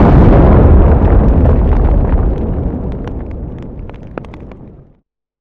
grenade_explode_far1.wav